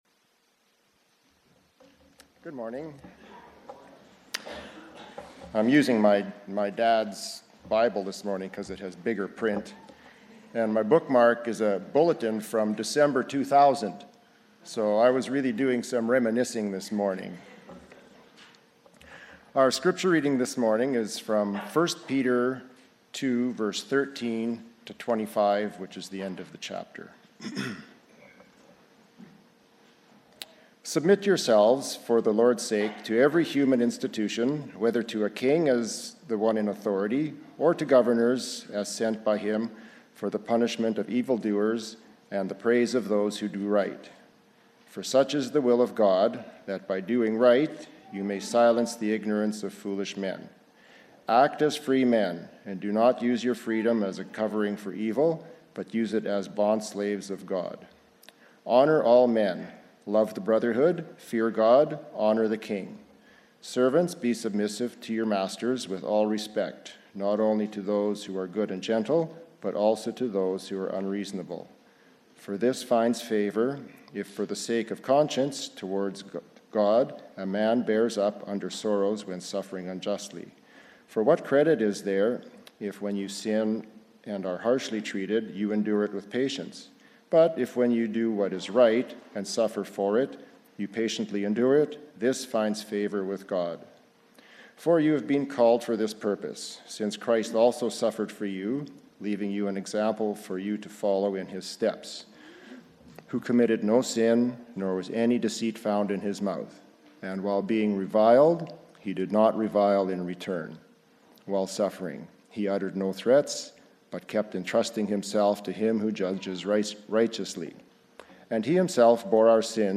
Sermons | Linden Mennonite Brethren Church